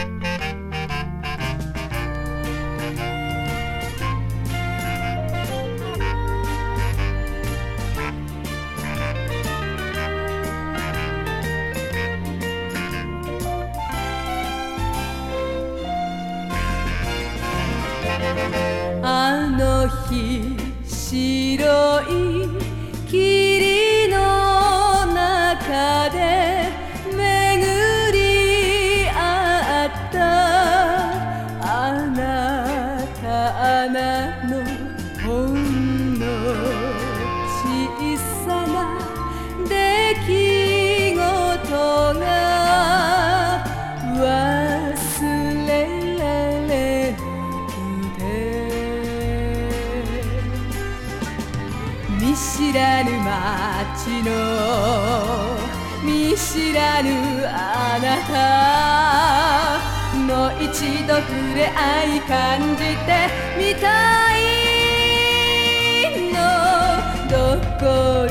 イージリスニング感触と歌謡曲の混ざり具合がナイス！やさしいメロディに癒されます。